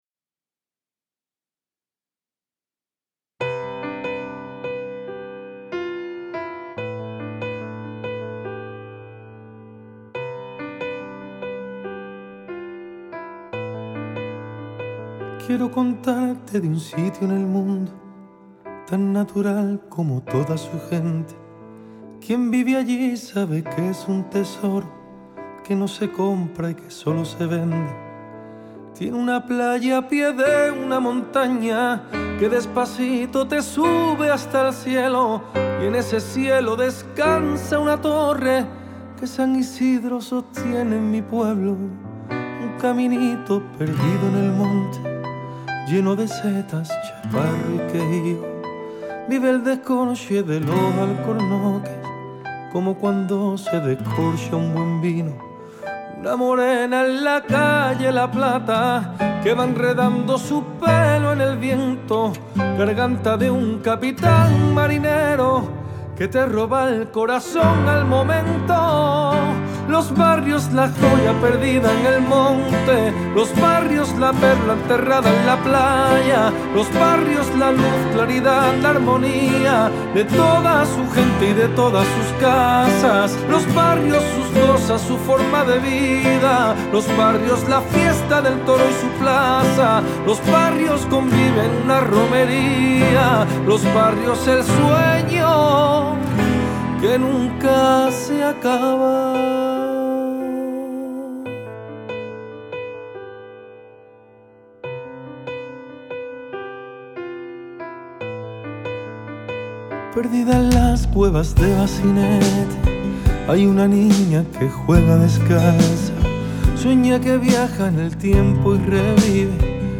canción, dedicada al municipio de Los Barrios, compuesta por Miguel Nández.